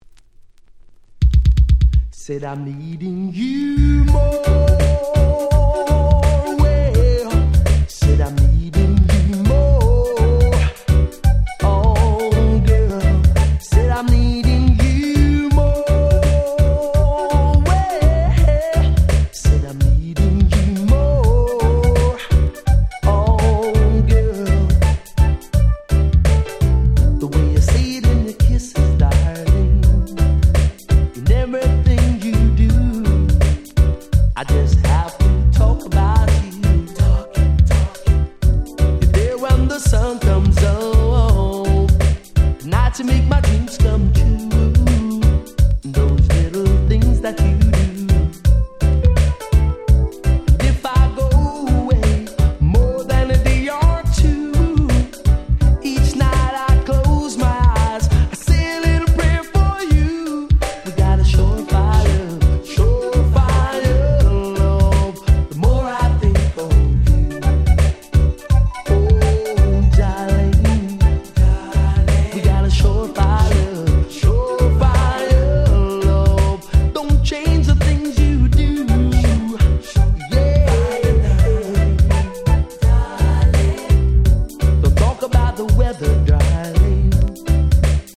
90' Super Hit Reggae / R&B !!